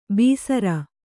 ♪ bīsara